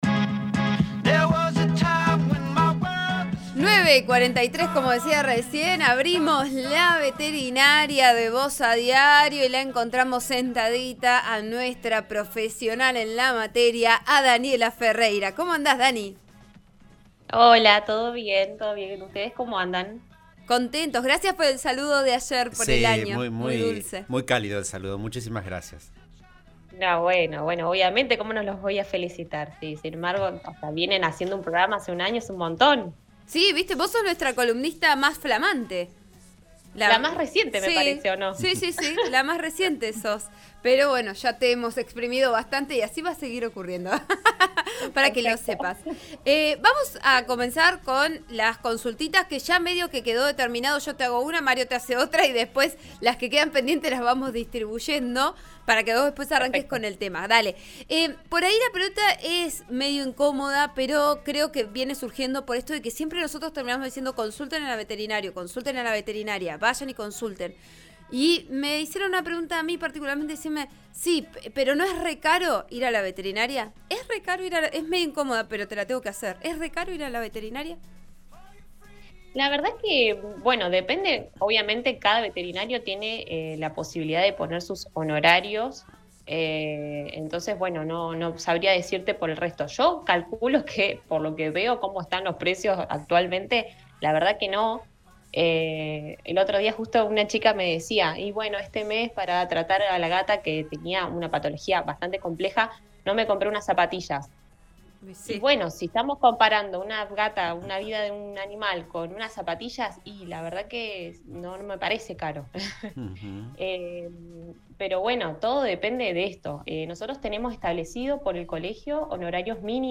Además, como todos los miércoles, respondió preguntas de nuestros oyentes: ¿Es caro ir a la veterinaria?